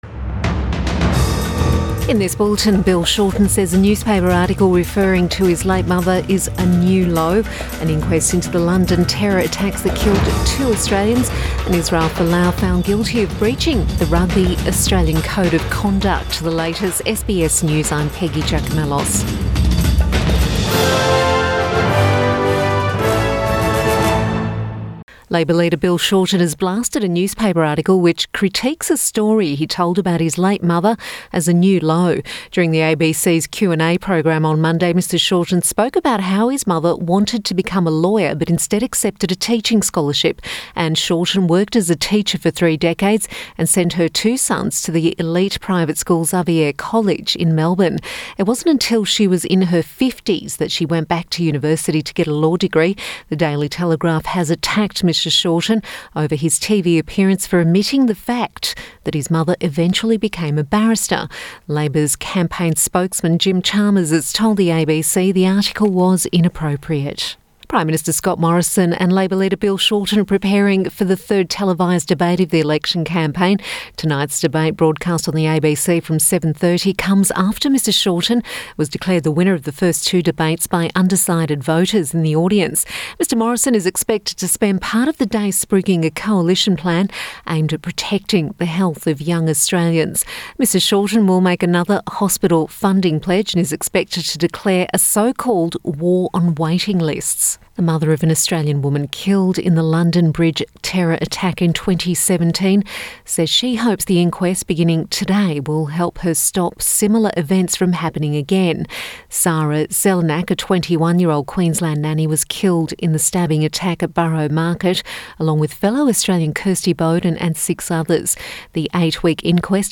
Midday bulletin 8 May